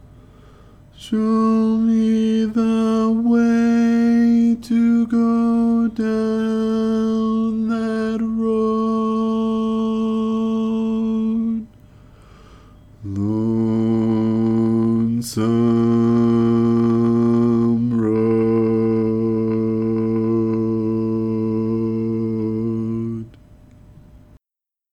Key written in: D Major
Type: Barbershop
Each recording below is single part only.